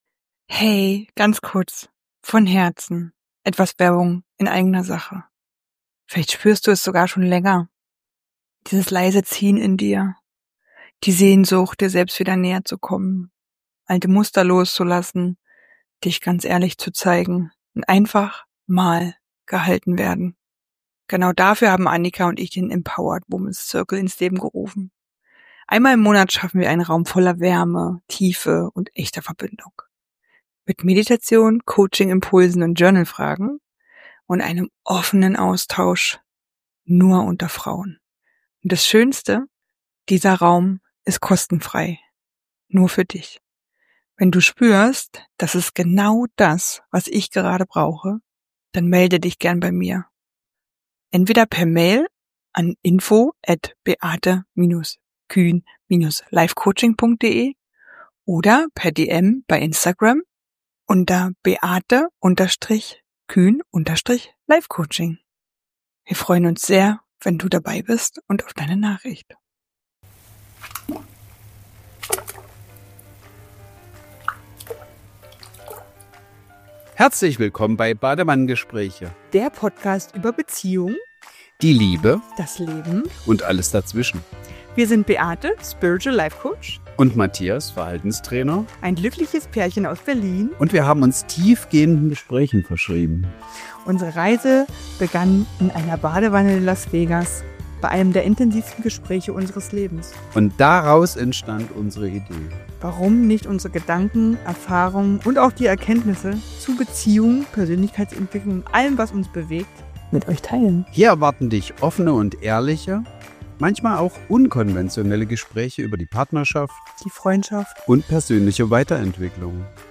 In dieser Solo-Folge von Badewannengespräche nehme ich dich mit in ein Thema, das so viele von uns betrifft – oft ohne, dass wir es benennen können: Mental Load.